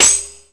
tambourin_lob01.mp3